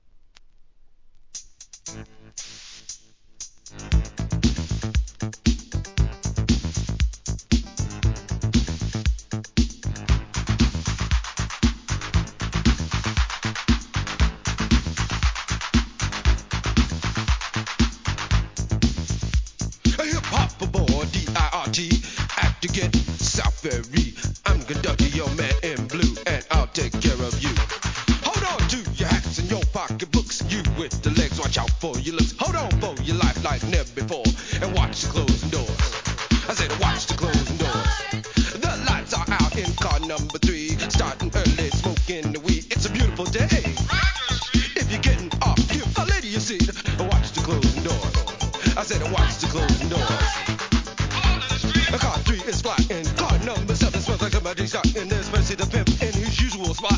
HIP HOP/R&B
エレクトロOLD SCHOOL CLASSIC!!!